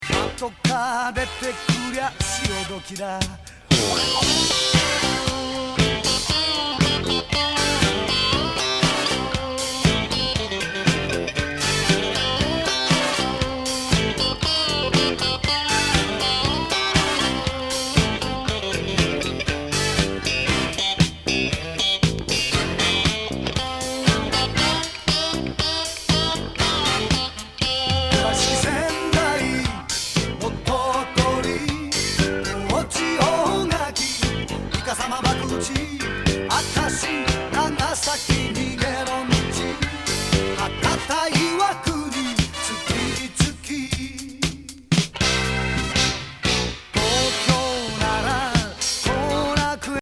ヤバめ和ファンキー・ディスコ